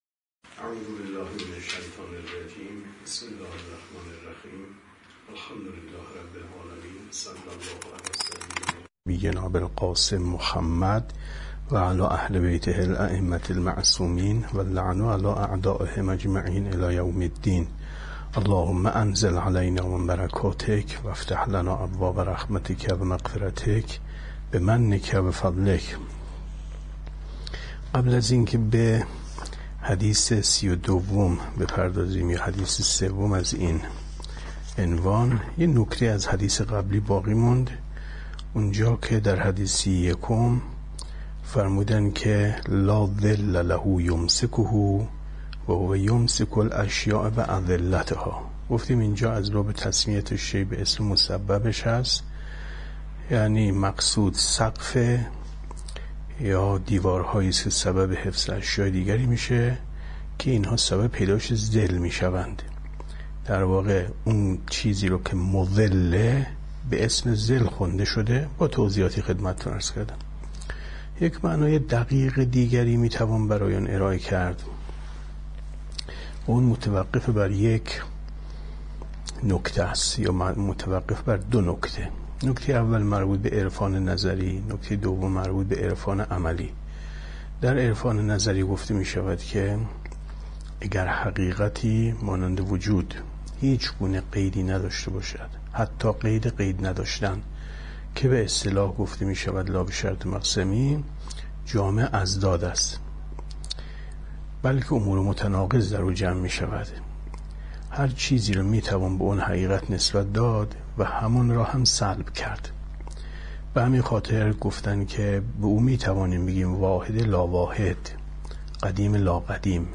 کتاب توحید ـ درس 42 ـ 21/ 10/ 95